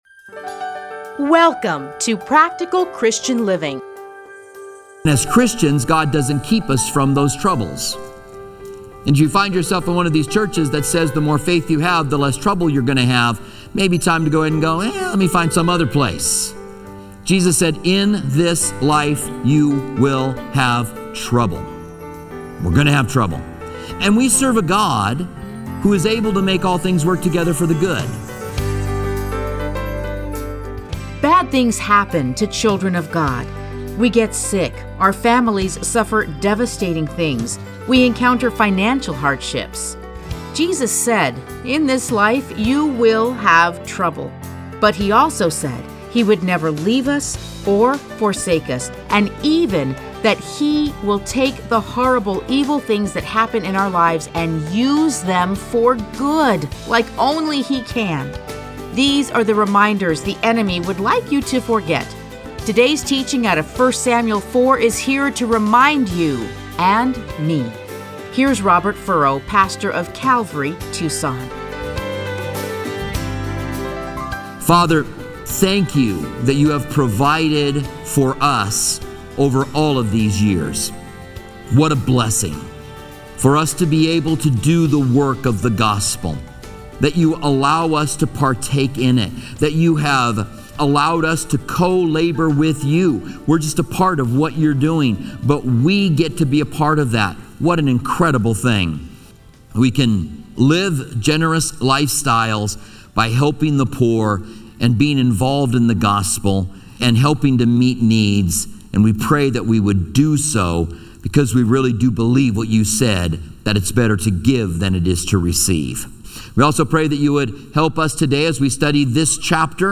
Listen to a teaching from 1 Samuel 4:1-22.